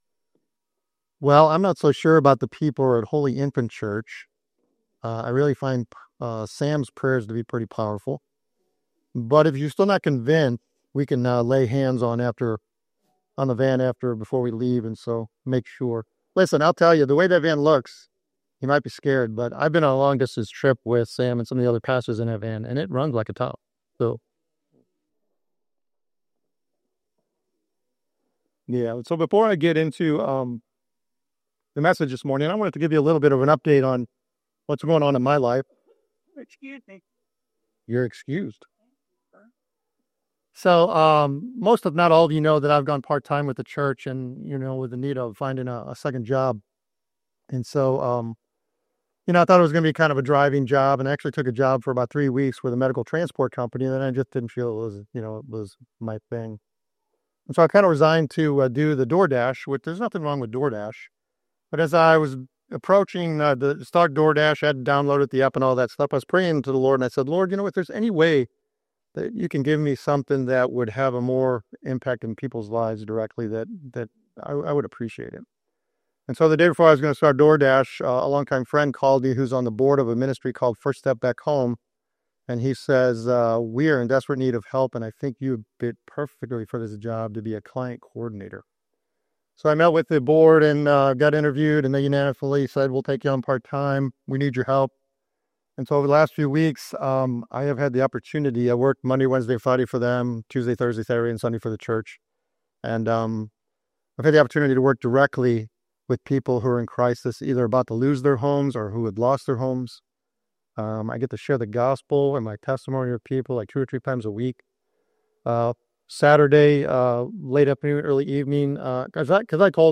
Drawing from Matthew 24, the message highlights the certainty and mystery of Jesus' second coming, urging believers to live in a state of constant spiritual alertness. Through the lens of Noah's time, the sermon warns against being consumed by the normalcy of life and encourages readiness for the unexpected.